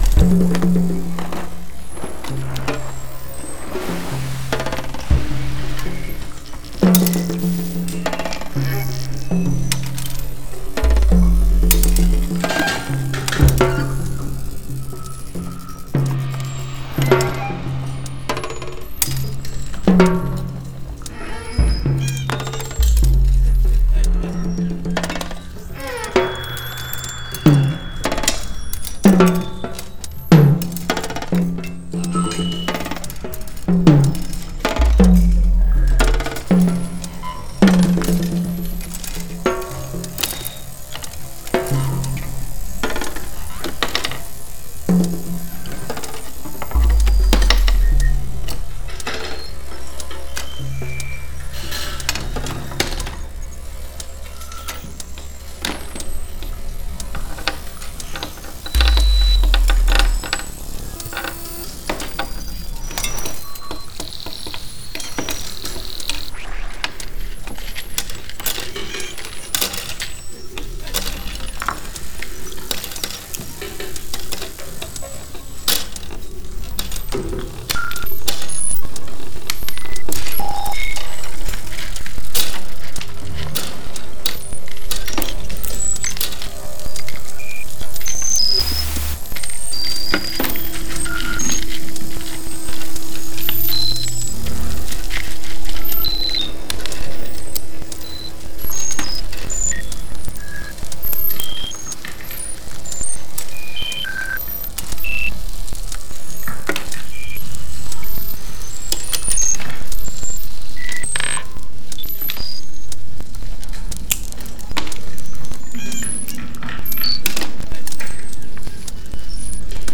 harpa
violoncelo e computador